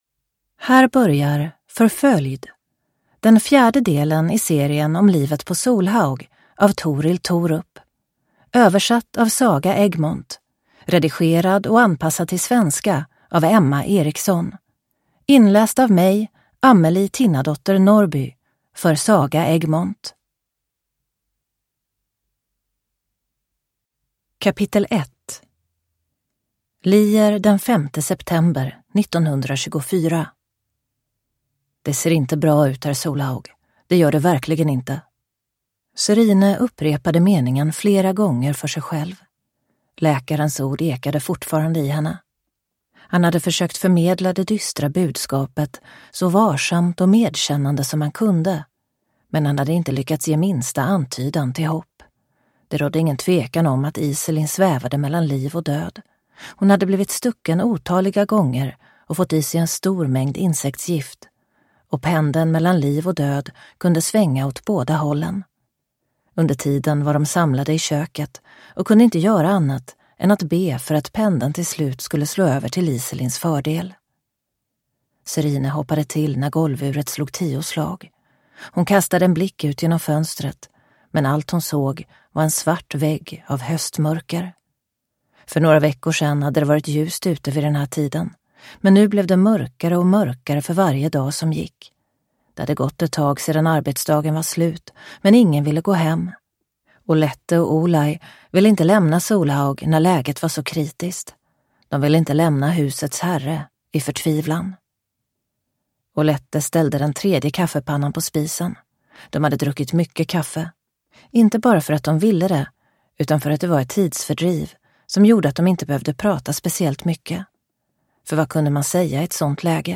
Förföljd (ljudbok) av Torill Thorup